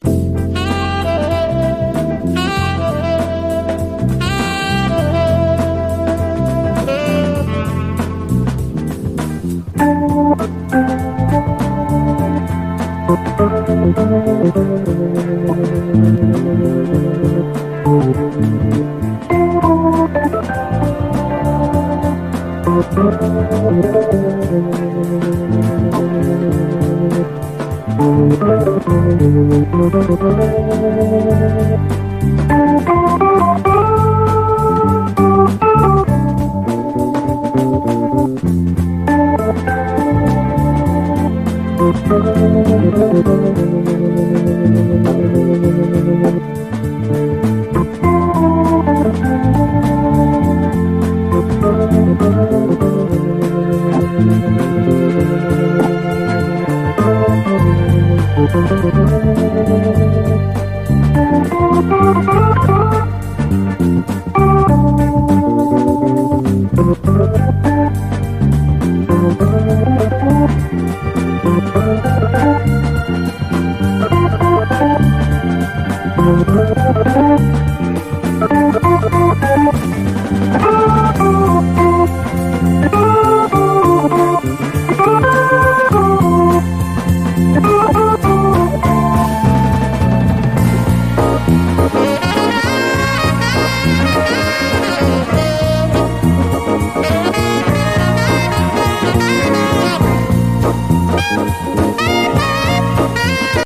JAZZ FUNK / SOUL JAZZ, JAZZ
オルガン・ソウル・ジャズ〜ジャズ・ファンク名盤！
ビシバシとビートも立ち、ネットリとしたメロウネスが充満する、グレイト・ジャズ・ファンク！